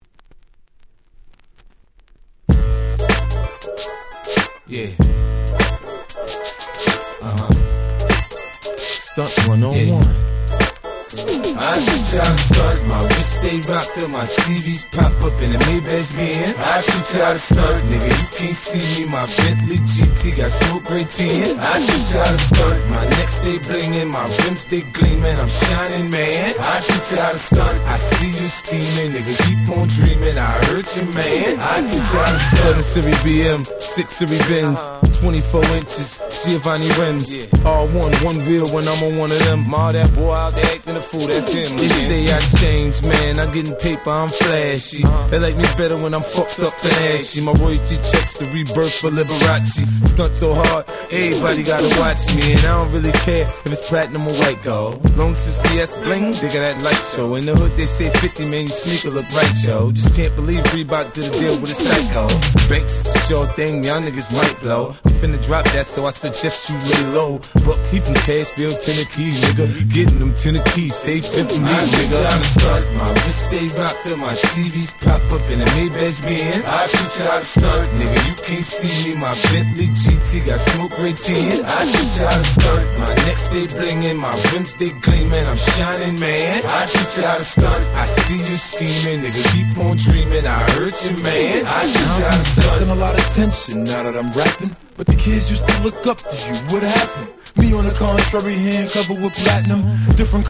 03' Super Hit Hip Hop !!